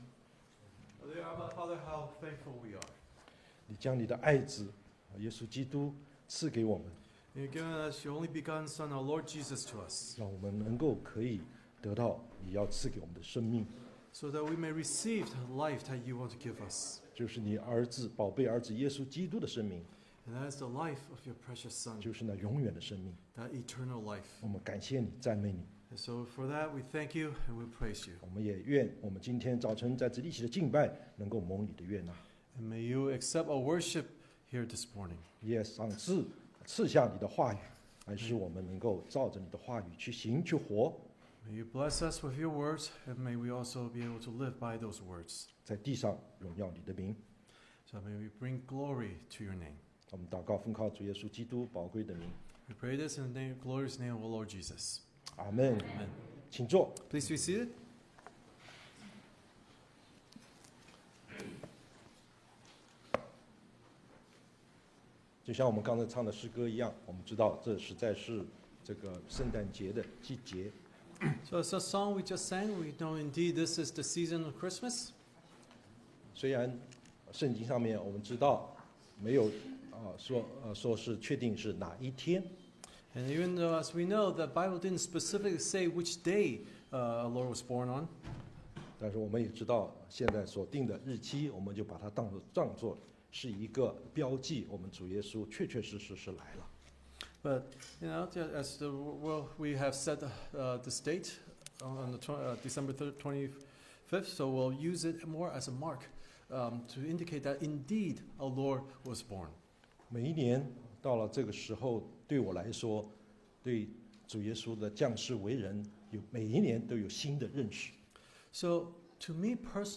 东区基督教会主日崇拜讲道信息